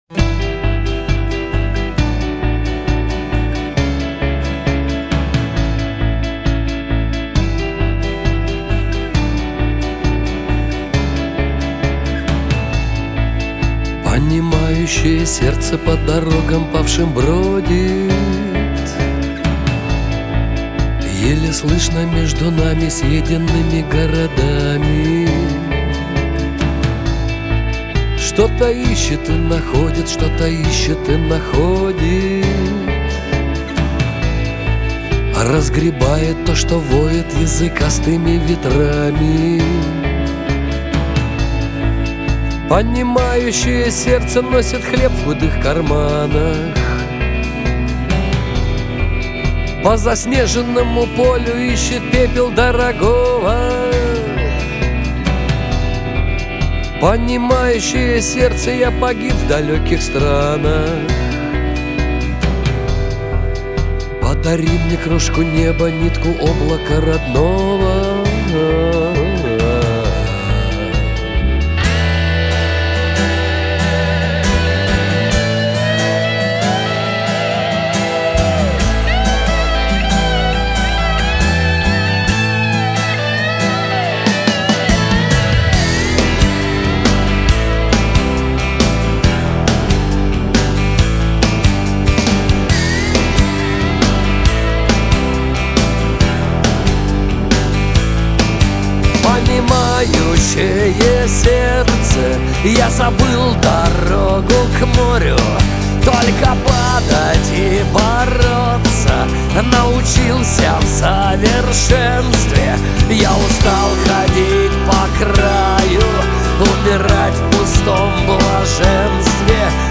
Руский рок
Русский рок